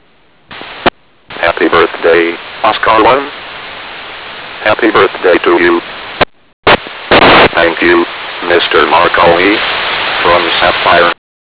Sapphire recordings for Oscar-1 and 100 years Marconi�s first transatlantic transmition: Full pass (800kb),